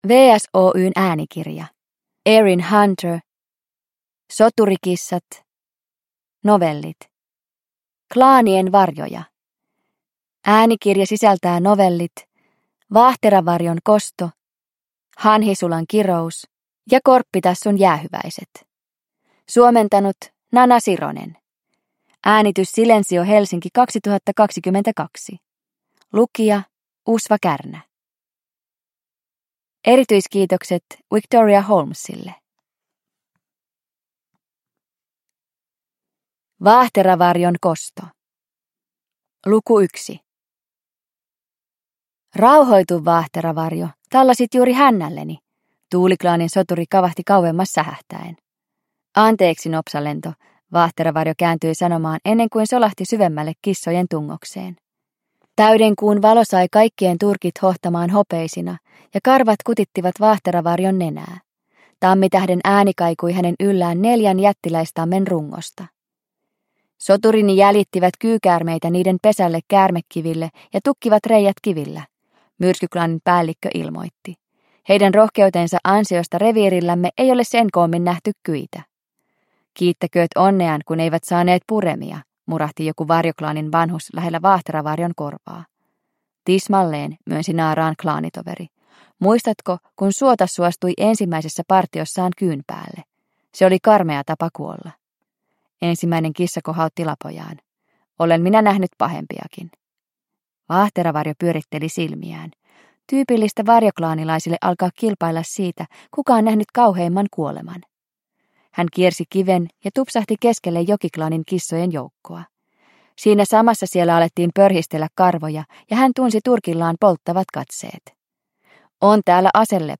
Soturikissat: Klaanien varjoja – Ljudbok – Laddas ner